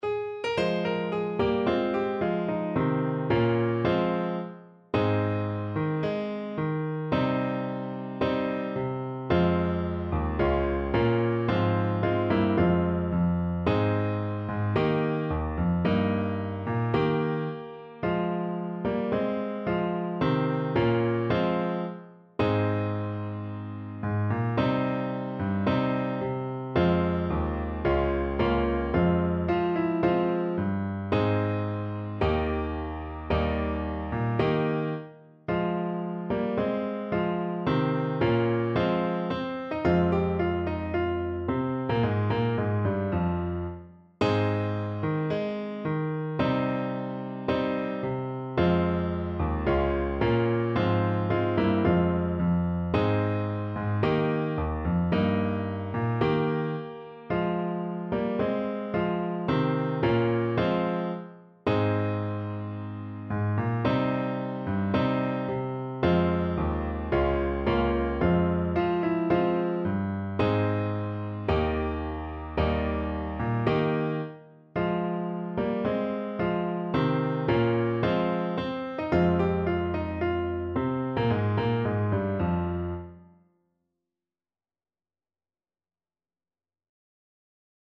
4/4 (View more 4/4 Music)
~ = 110 Allegro (View more music marked Allegro)